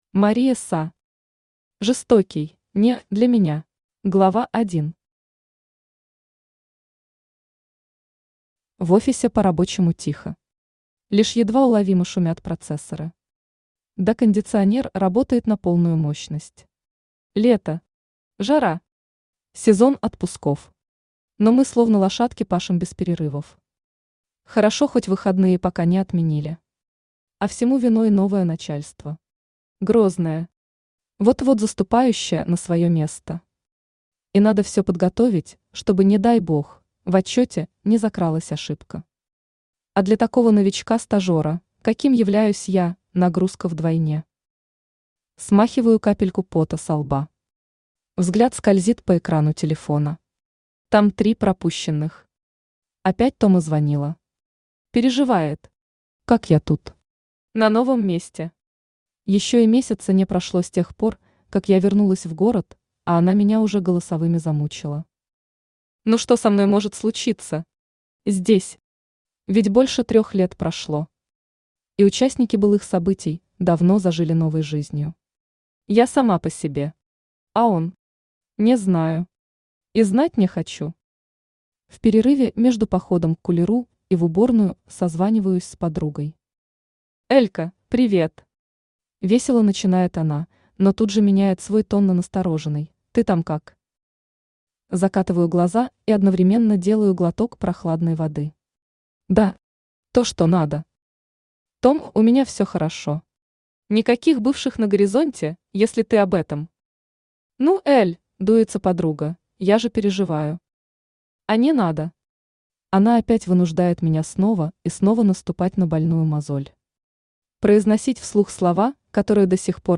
Aудиокнига Жестокий (не) для меня Автор Мари Са Читает аудиокнигу Авточтец ЛитРес. Прослушать и бесплатно скачать фрагмент аудиокниги